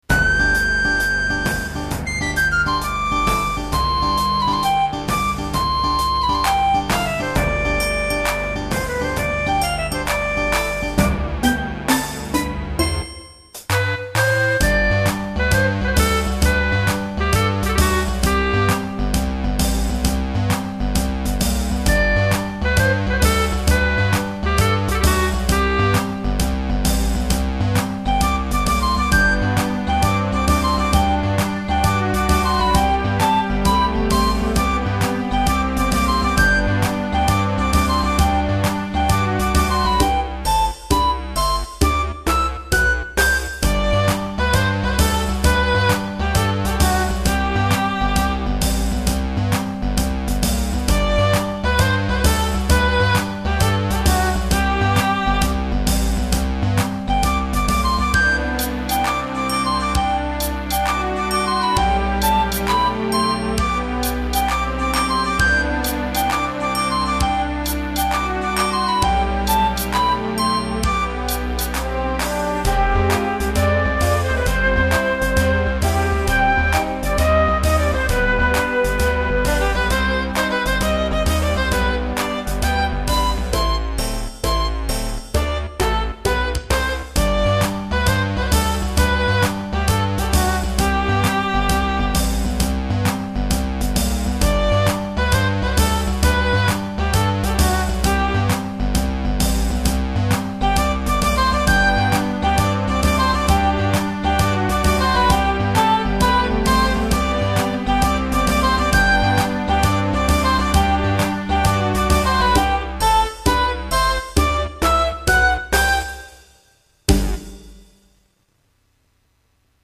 音採りはしっかりしていますが、バランスや聞かせ方などの完成度がイマイチかも。
オススメ度☆☆☆（MP3の音量が小さめですが、それを直す気が起きないぐらい＾＾；）